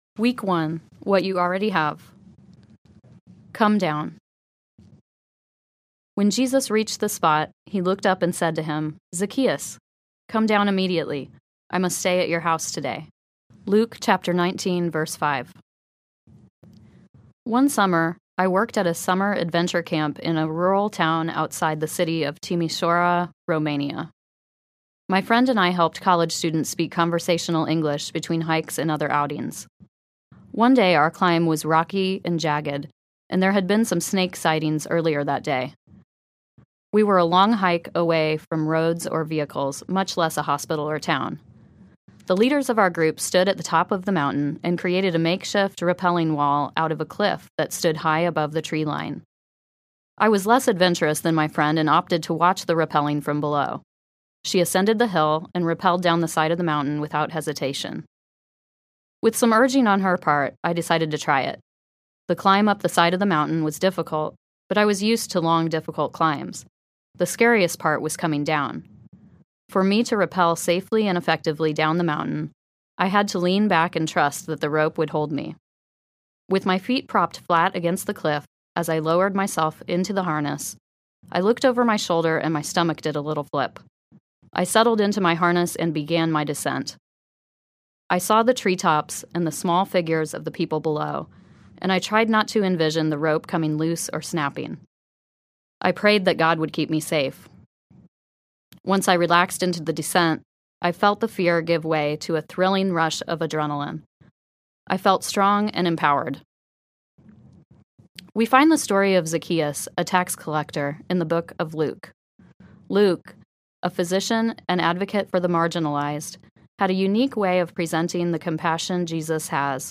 Revealed Audiobook